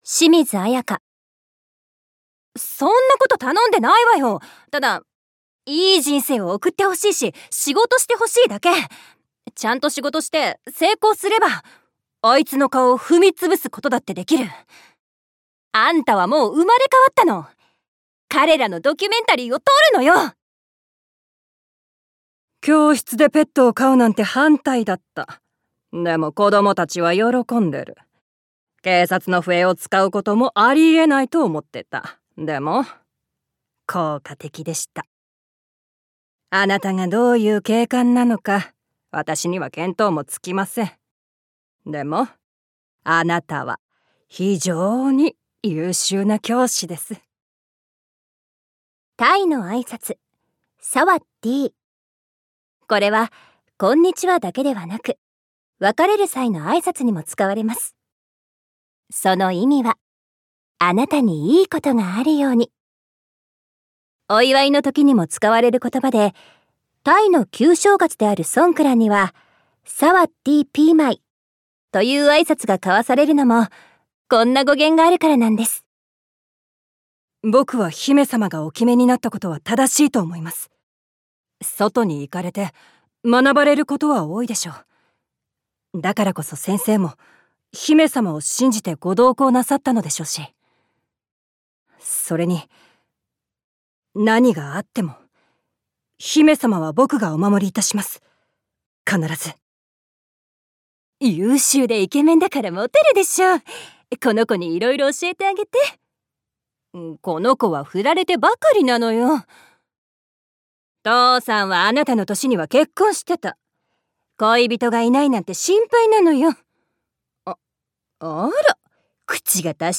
サンプルボイス
関西弁